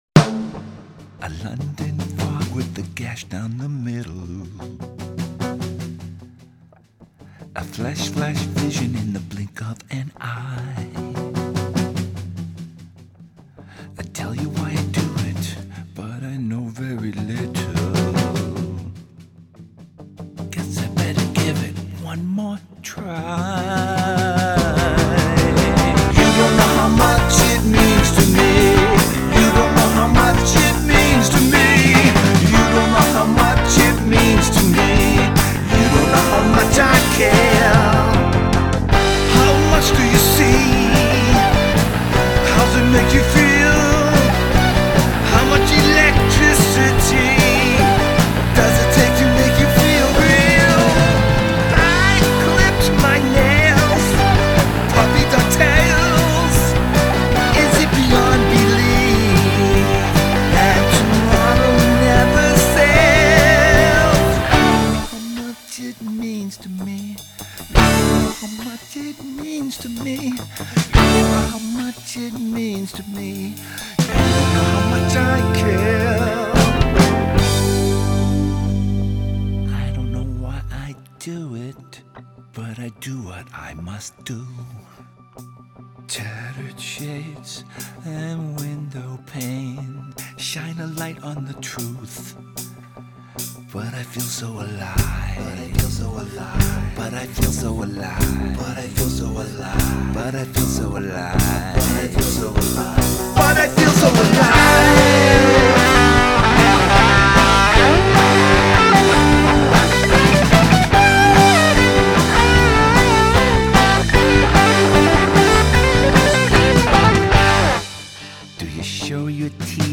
The confident vocal delivery is great as always.